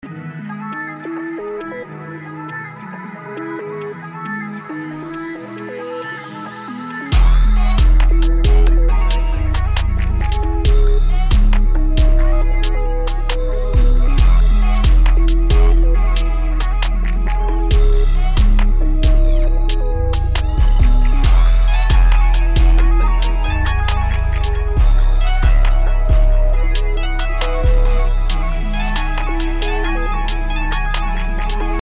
BPM: 136
Key: C# minor
Preview del beat: